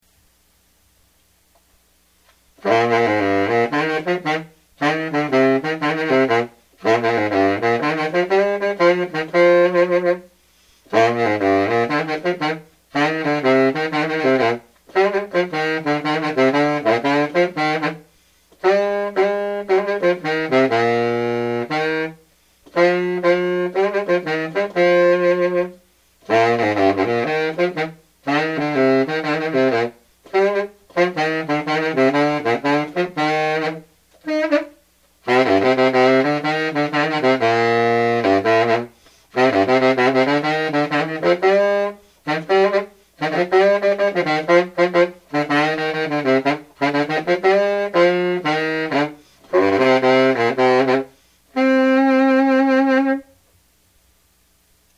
BARITONES
"Silver Elkhart Bari" Listen to a Silver Martin Elkhart Bari made in the mid twenties play Tramp Tramp Tramp and Johnny Comes Marching Home.